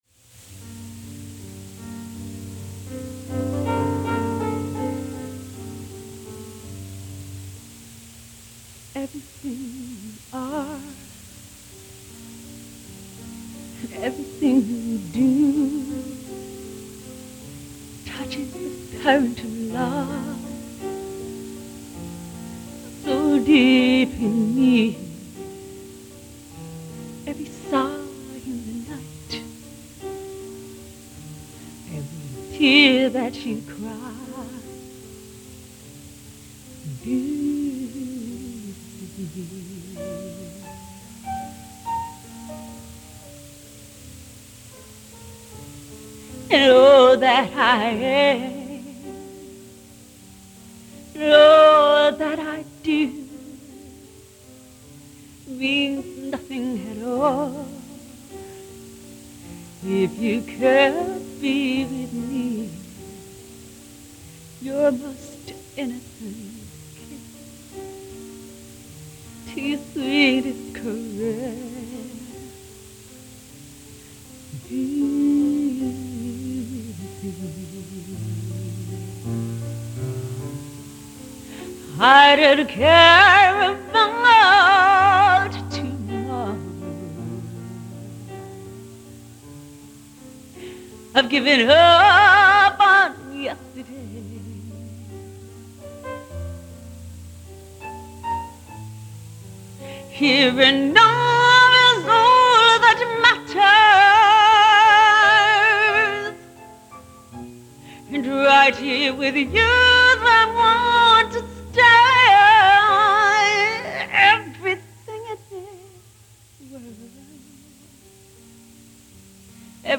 done in studio